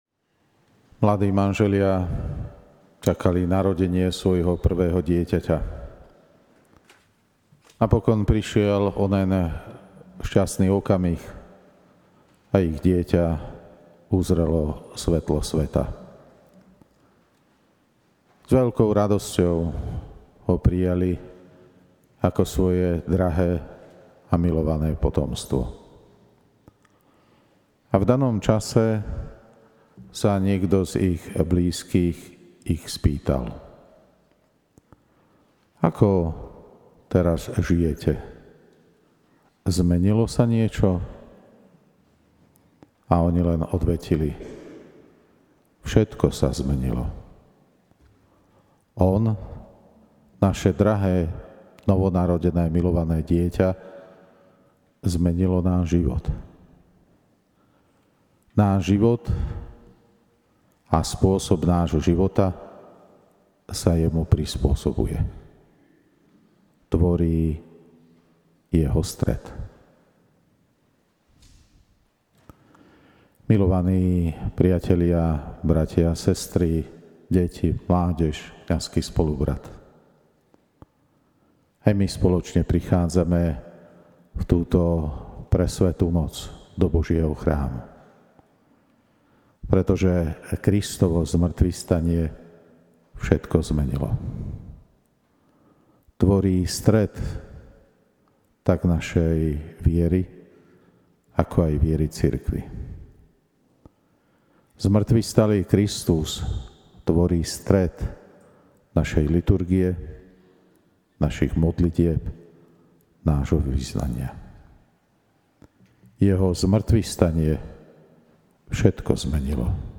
Nahrávku homílie